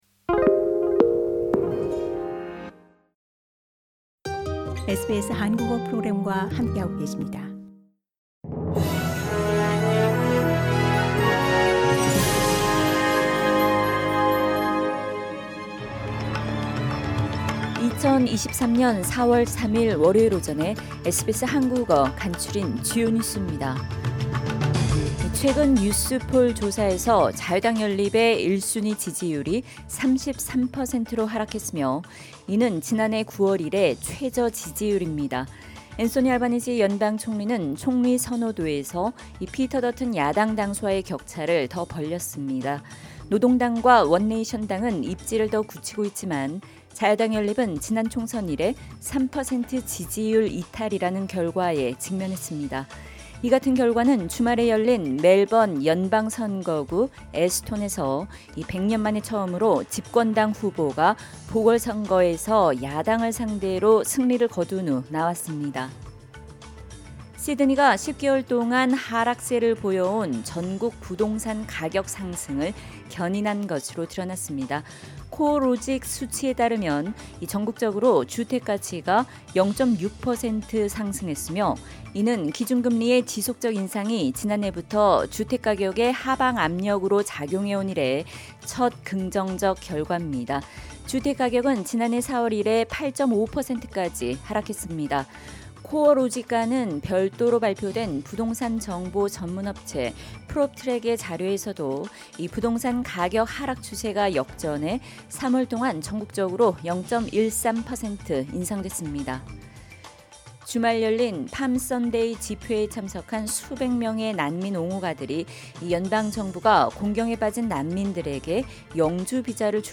SBS 한국어 아침 뉴스: 2023년 4월 3일 월요일